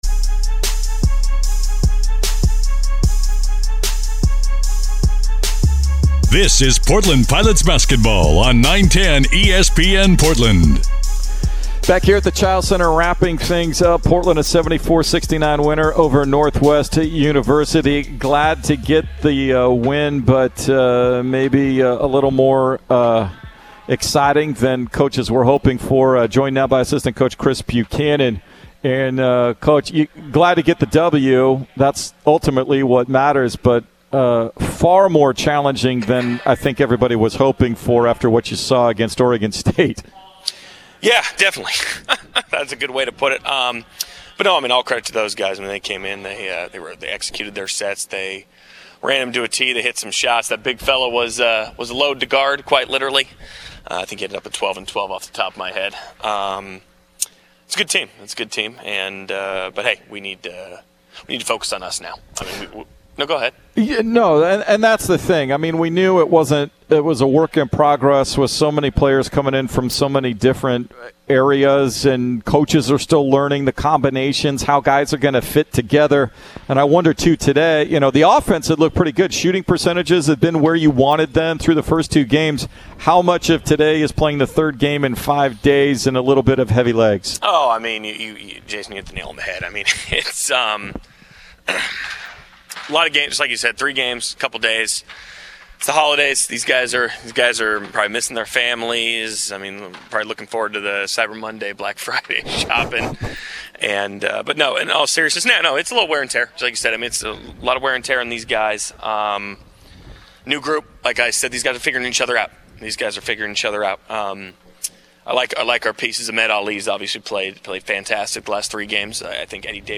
Men's Hoops Post-Game Interview vs. Northwest U